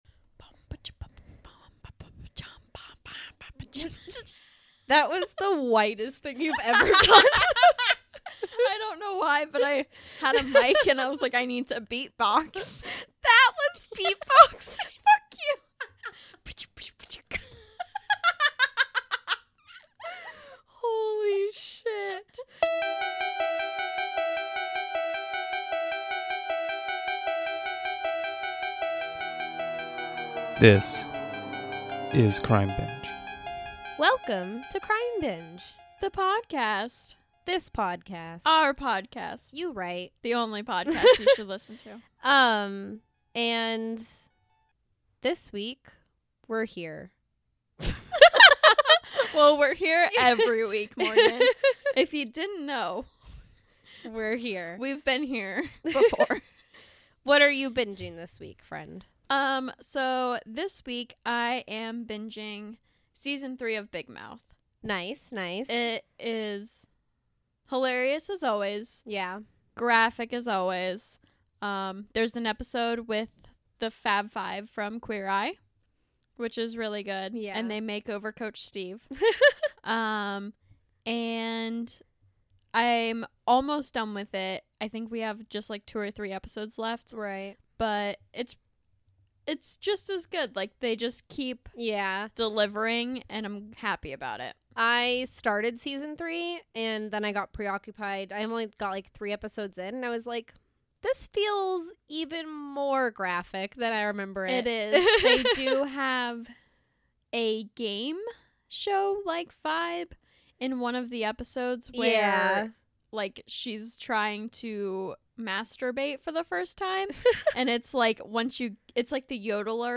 This week on Crime Binge the girls talk about the tragic murder of Matthew Shepard, a gay man who was murdered for being who he is.